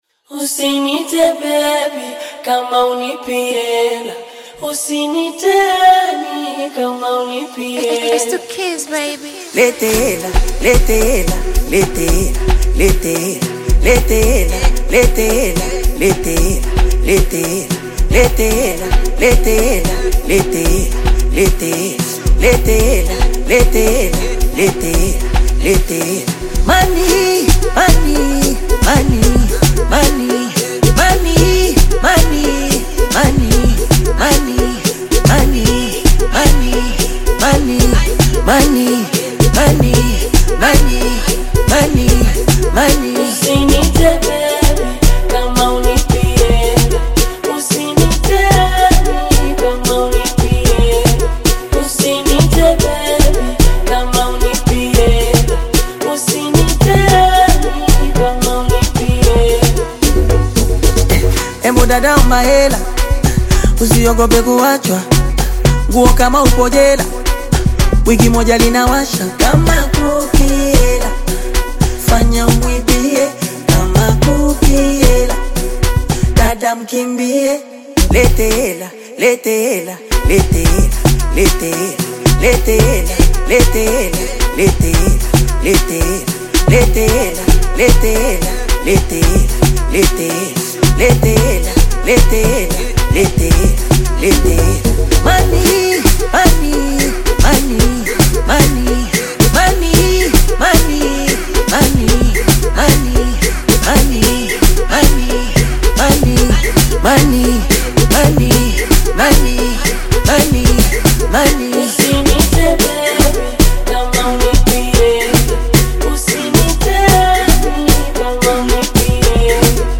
dynamic vocals
a captivating melody